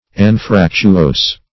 Search Result for " anfractuose" : The Collaborative International Dictionary of English v.0.48: Anfractuose \An*frac"tu*ose`\ (?; 135), a. [See Anfractuous .]
anfractuose.mp3